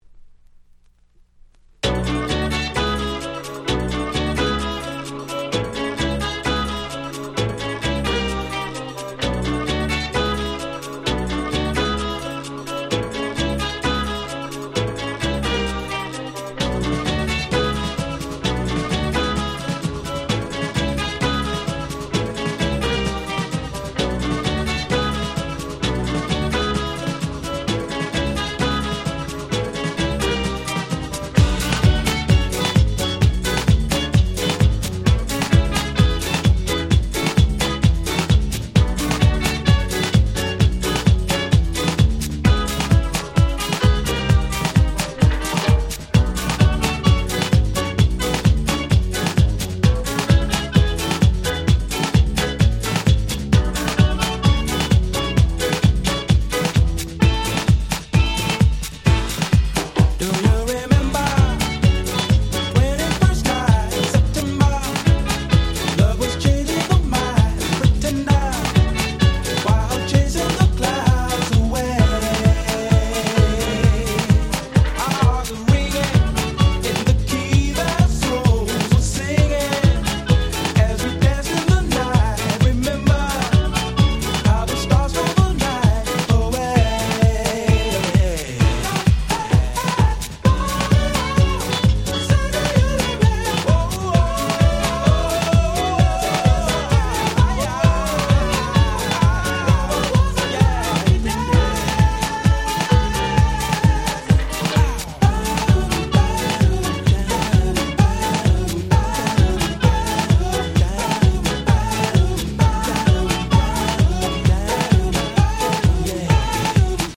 02' Very Nice Dance Classics Remixes !!